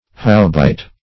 Search Result for " howbeit" : The Collaborative International Dictionary of English v.0.48: Howbeit \How*be"it\, conj.
howbeit.mp3